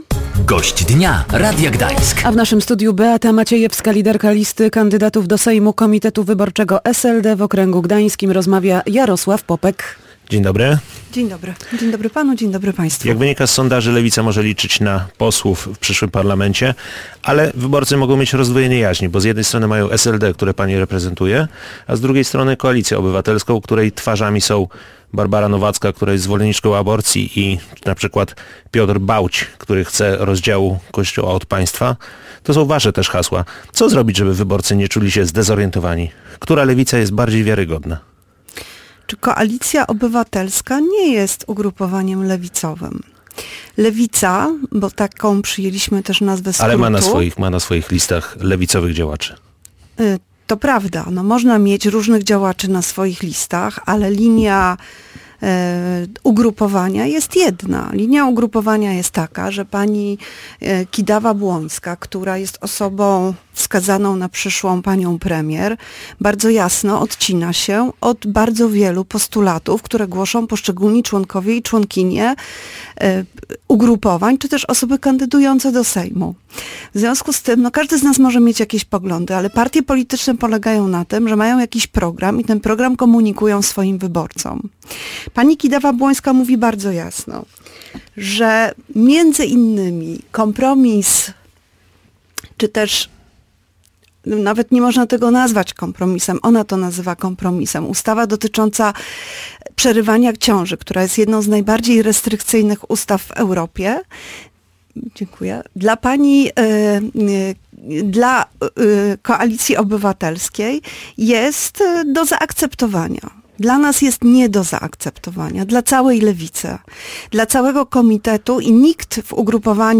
– Rodzice nie decydują o tym, jakiego rodzaju lekcje odbywają się na innych przedmiotach, czego uczy się na historii czy matematyce – stwierdziła Beata Maciejewska, liderka listy Komitetu Wyborczego SLD w wyborach do Sejmu w okręgu gdańskim, która była Gościem Dnia Radia Gdańsk. Lewica chce wprowadzić edukację seksualną w szkołach.